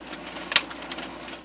servo4.wav